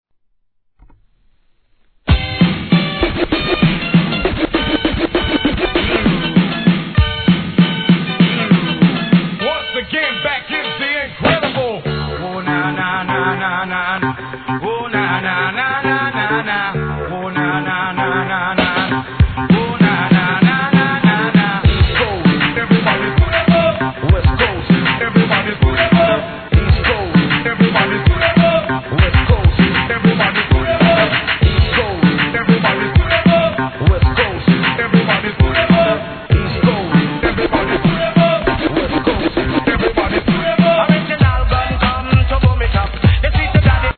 HIP HOP/R&B
ラガテイスティーな好作品!!